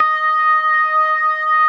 Index of /90_sSampleCDs/InVision Interactive - Keith Emerson - The Most Dangerous Synth and Organ/ORGAN+SYNTH1